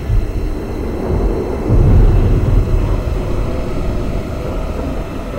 Alarm2_2.ogg